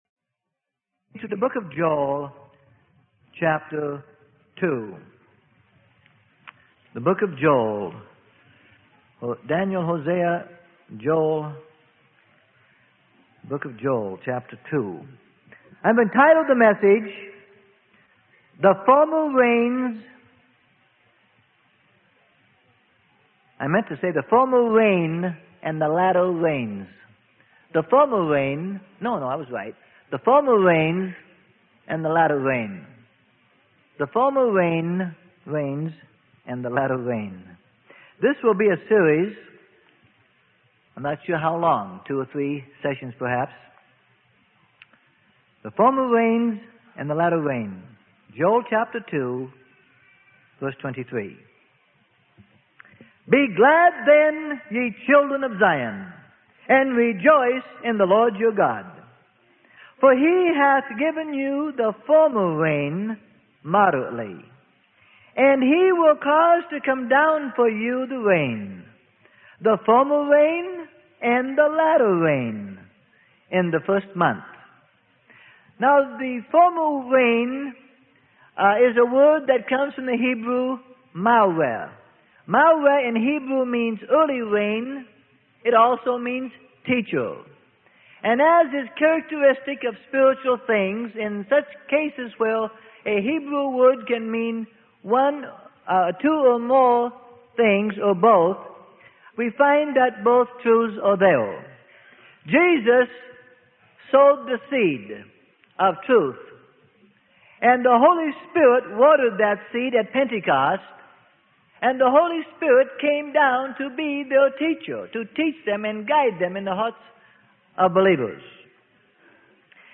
Sermon: The Former Rains and the Latter Rain - Part 1 - Freely Given Online Library